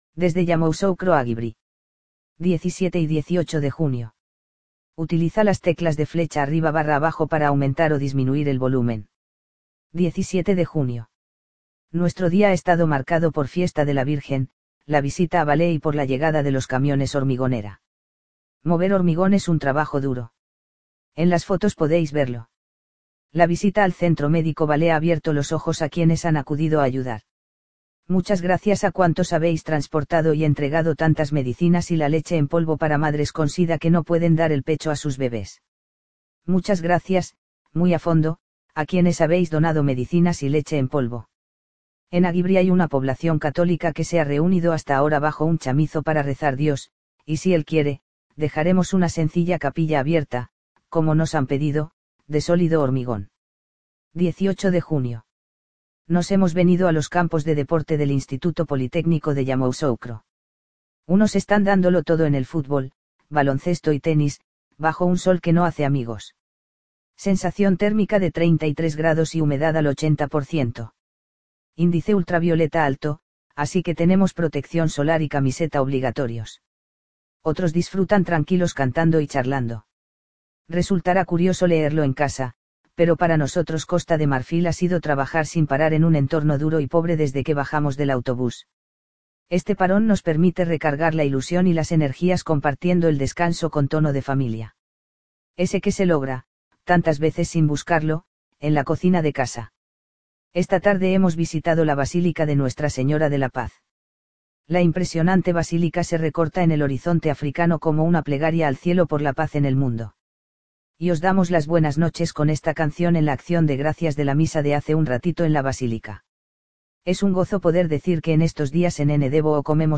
Y os damos las buenas noches con esta canción en la acción de gracias de la Misa de hace un ratito en la Basílica.